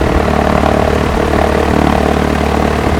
飛行機の爆音（3sec, 600KB）
搭乗した飛行機（翼が胴体の下部にある、Piper型と言うそうです。）
pipersound0928a.wav